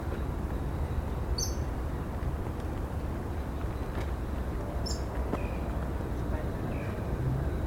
Rufous-sided Crake (Laterallus melanophaius)
Life Stage: Adult
Condition: Wild
Certainty: Observed, Recorded vocal